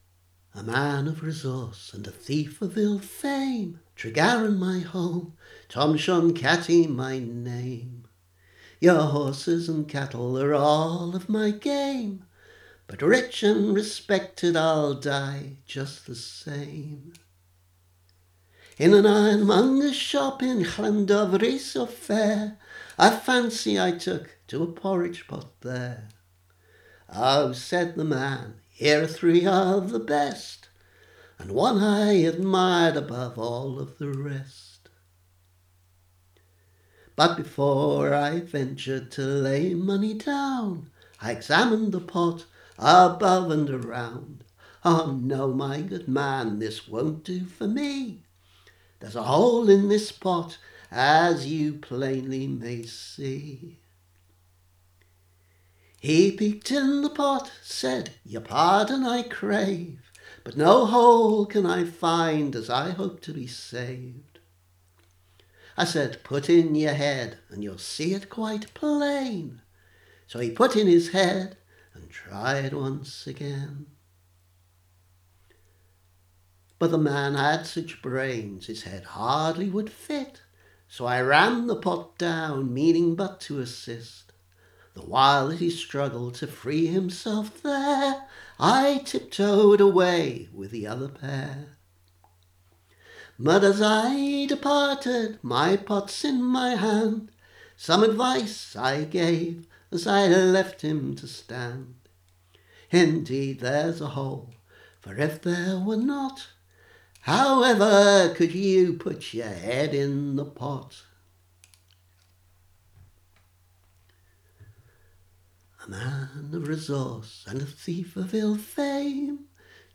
Three traditional tunes for the price of one